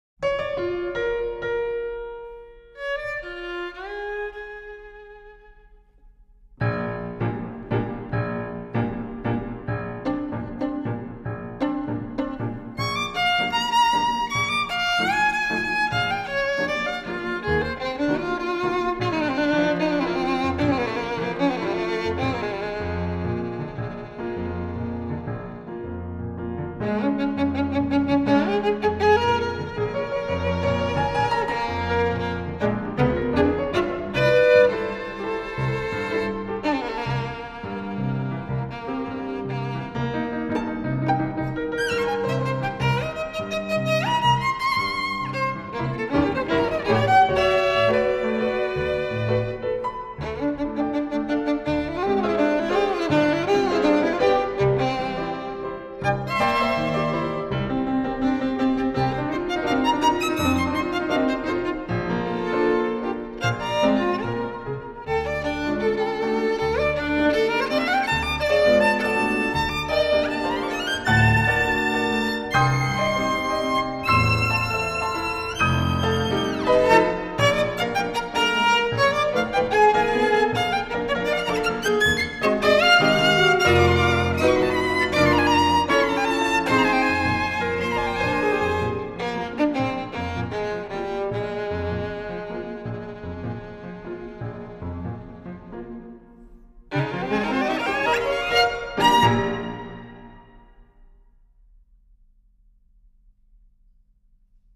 klavír